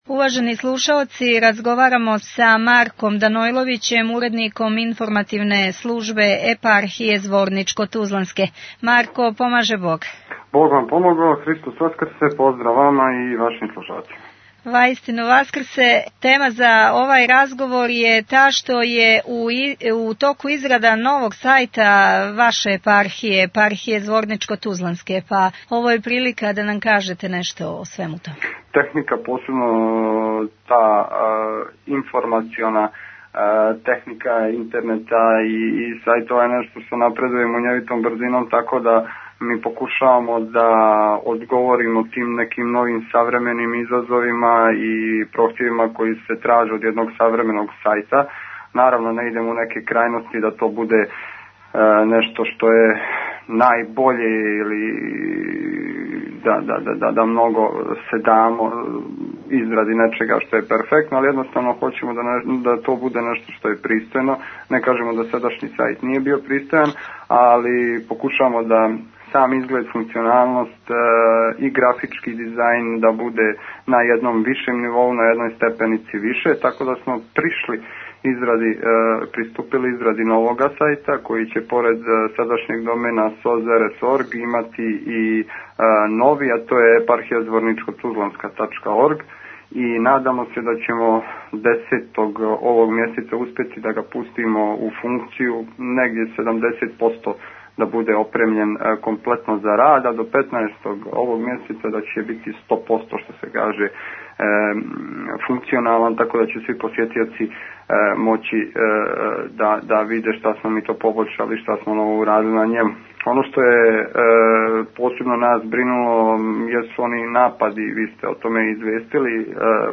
Актуелни разговори